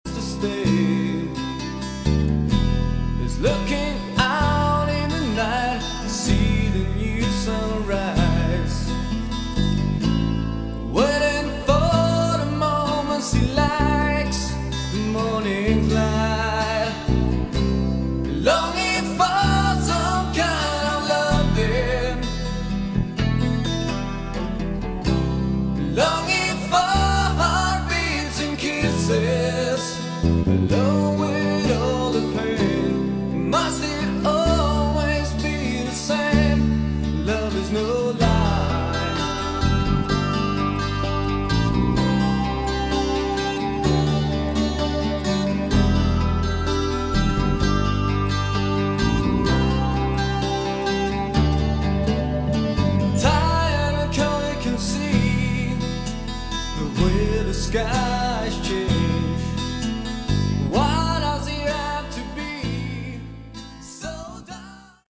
Category: Hard Rock
Vocals, Guitar, Bass, Keyboards, Drums